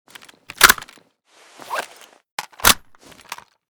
fn2000_reload.ogg.bak